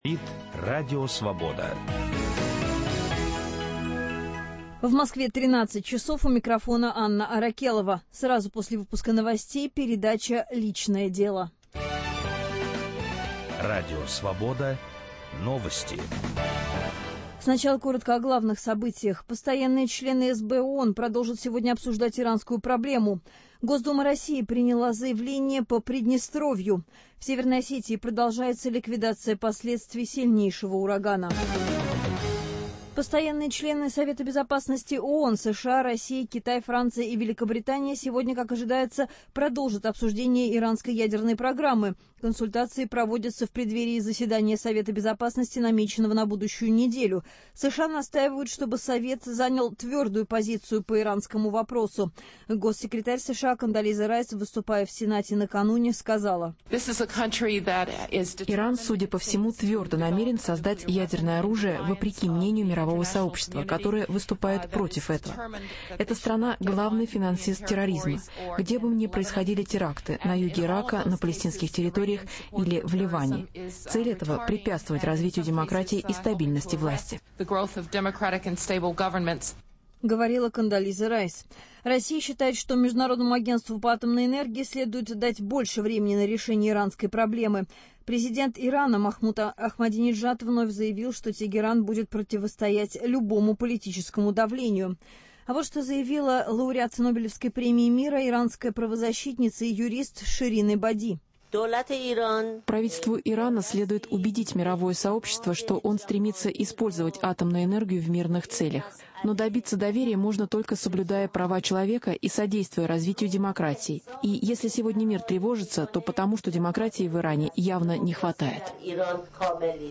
Знаете ли вы случаи, когда детская ложь была отнюдь не безопасной и небезобидной? 10 марта в прямом эфире мы поговорим о детском вранье, о самых разнообразных его формах - от безобидного фантазирования до лжесвидетельства.